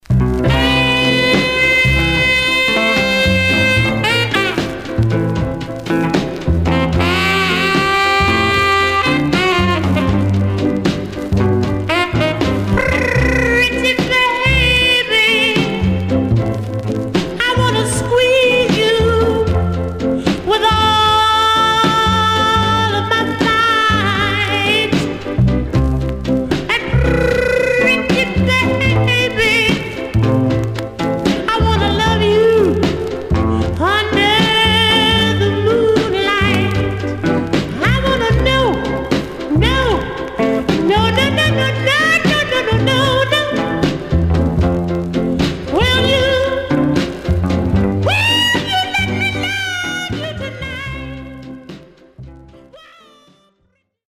Rythm and Blues Condition